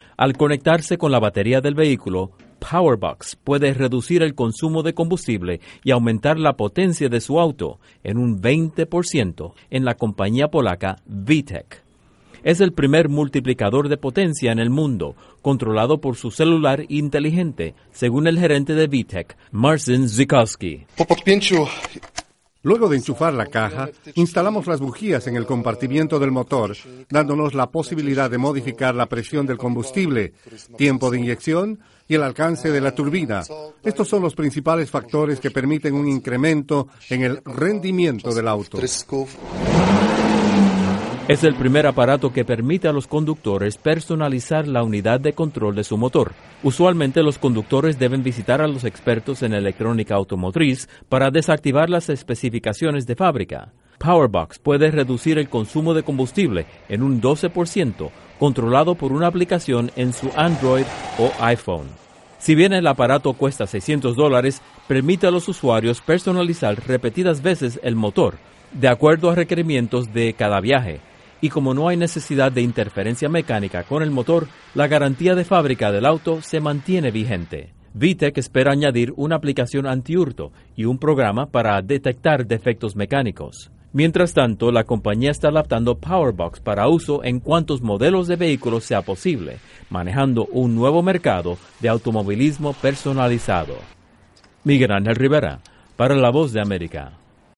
Informa desde los estudios de la Voz de América en Washington